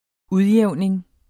Udtale [ -ˌjεwˀneŋ ]